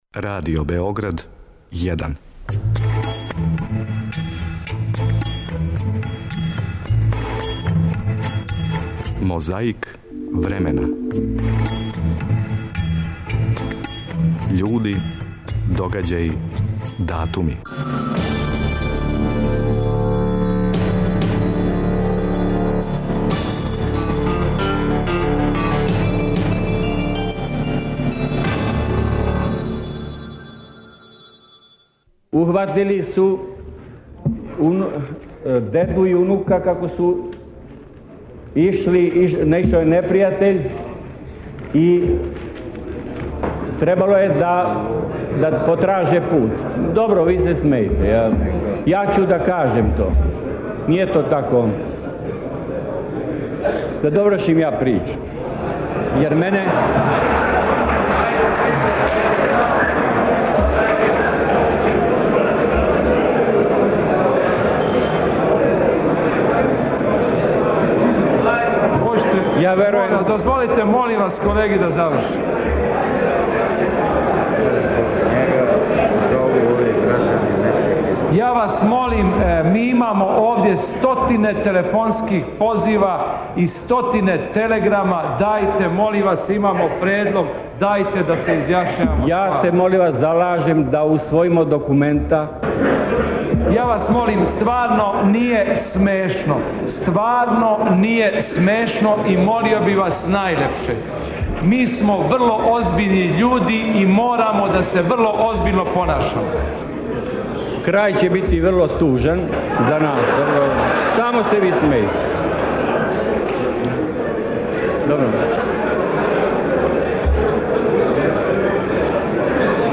У Мозаику времена говори народ и политичари (уколико се не утврди да су и политичари народ...)